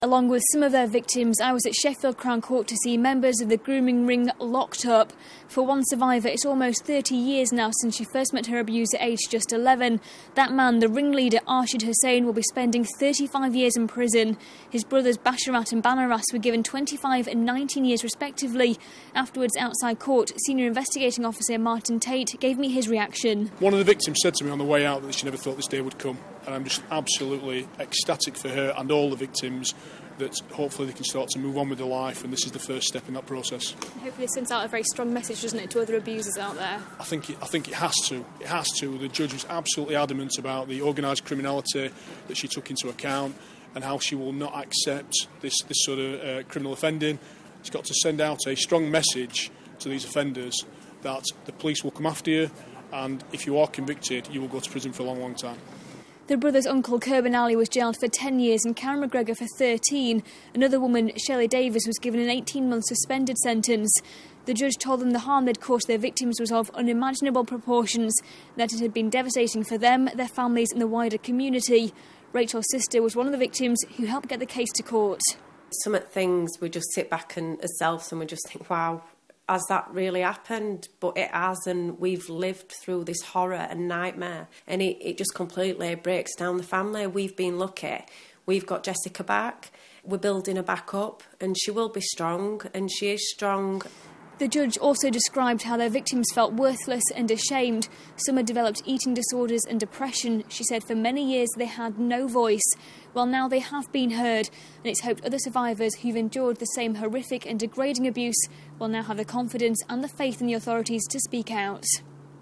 was at Sheffield Crown Court for the sentencing.